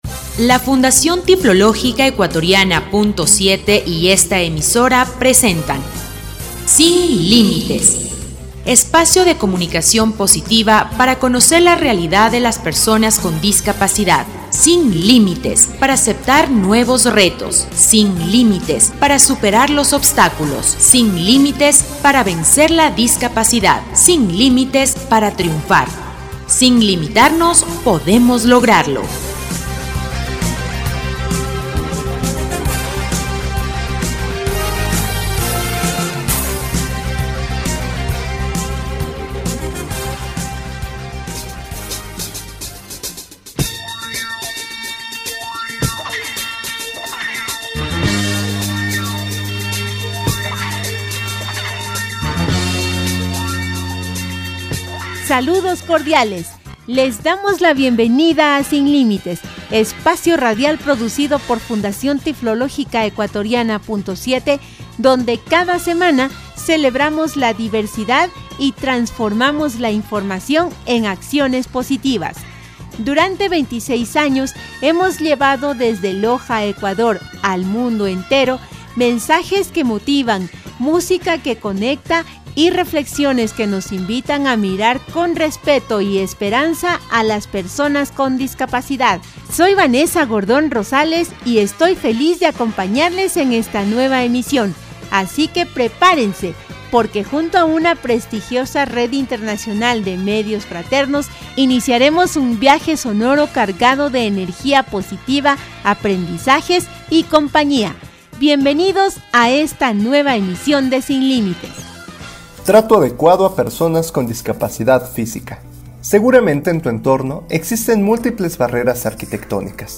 Espacio de comunicación positiva para conocer la realidad de las personas con discapacidad, disfruta de una nueva edición del programa radial «Sin Límites».